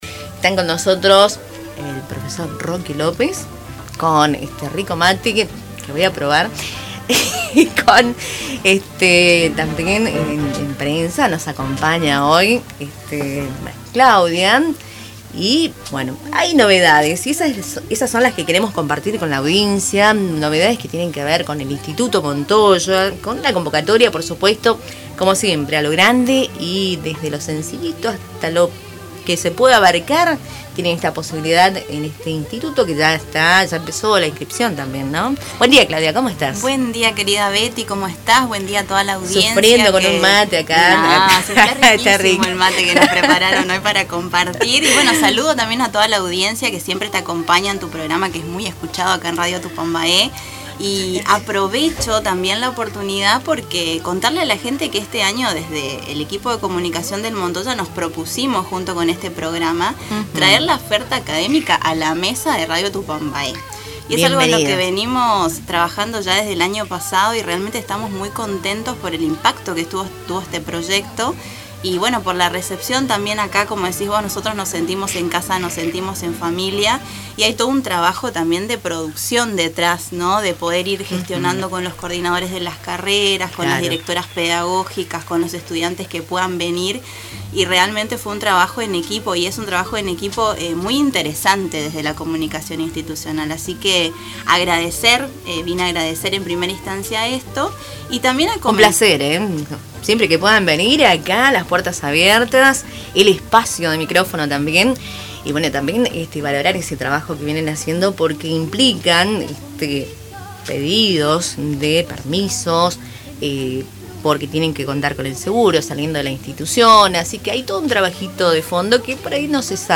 durante una entrevista en el programa Cultura en Diálogo de Radio Tupambaé.